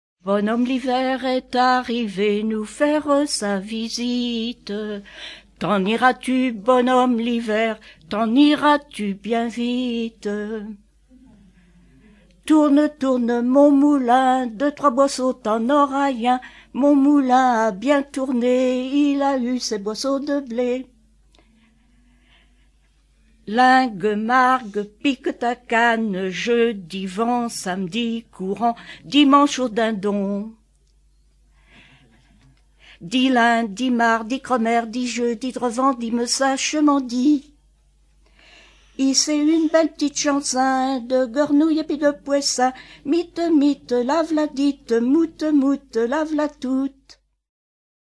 Suite de formulettes enfantins
Pouzauges ( Plus d'informations sur Wikipedia ) Vendée
chants brefs - cris de rue